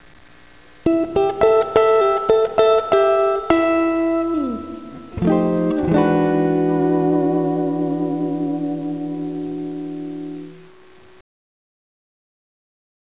To hear my stratocaster click here.
Today my main rig consists of a 1988 Strat plus, a 1972 bassman 50, an ART SGX T2, and a 4X12 cabinet.
guit.wav